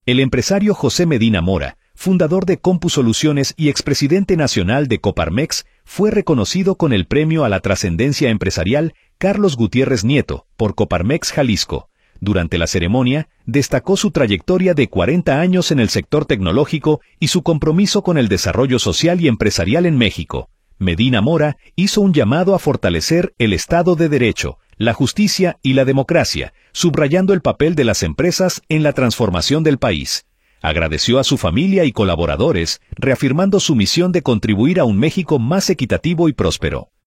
Durante la ceremonia, destacó su trayectoria de 40 años en el sector tecnológico y su compromiso con el desarrollo social y empresarial en México.